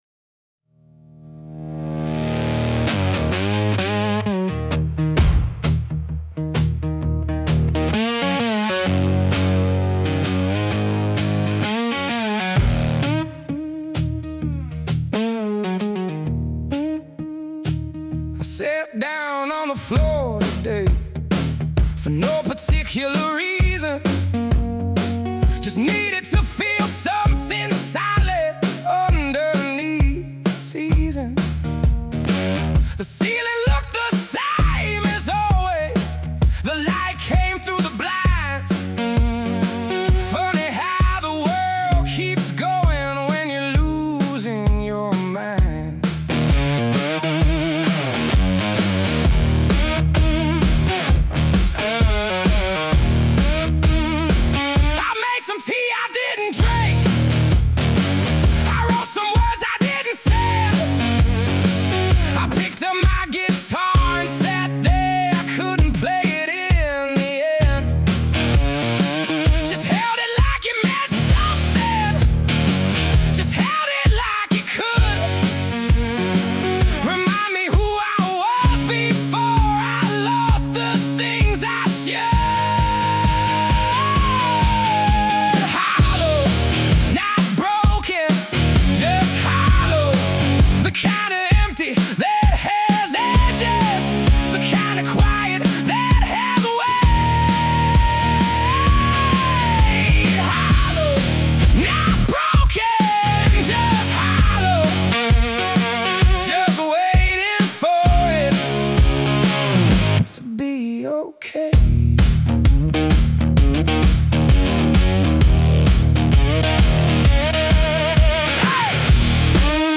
Made with Suno
delta blues garage rock, blues rock, garage punk